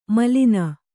♪ malina